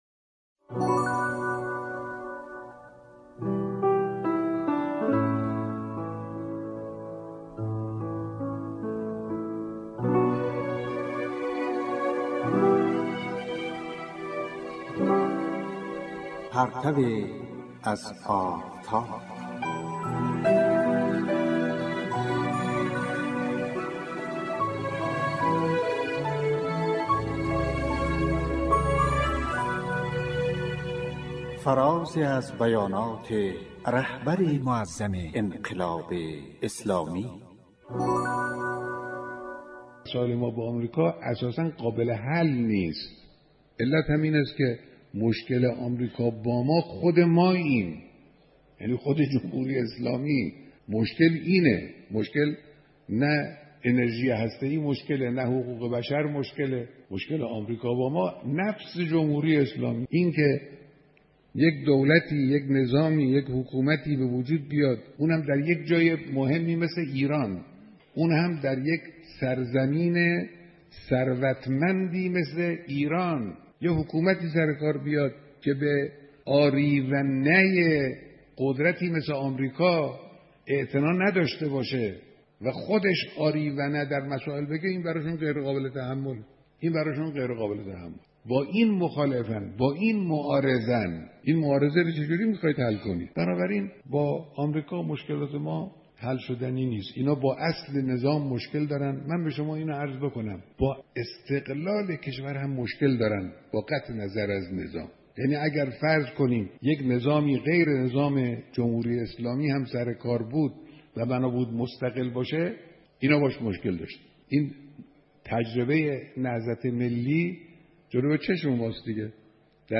گزیده ای از سخنان و رهنمودهای مقام معظم رهبری